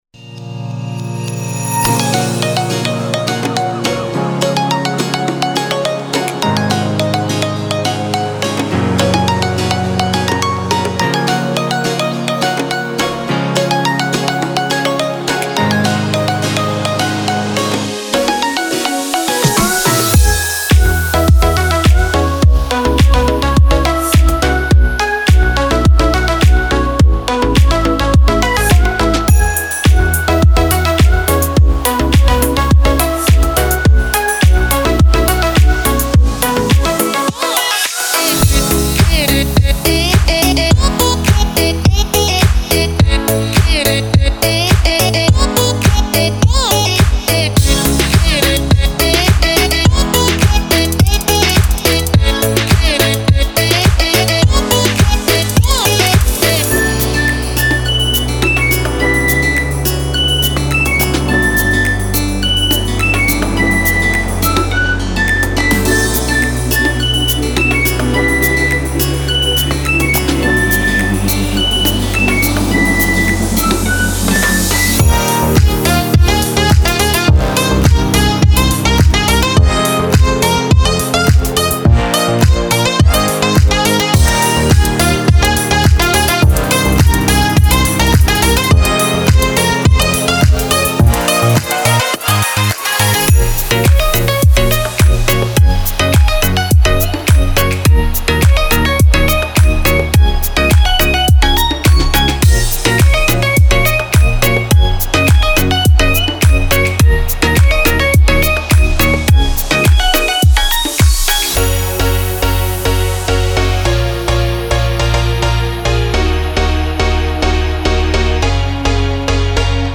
tropical house inspired patches for serum synth
MP3 DEMO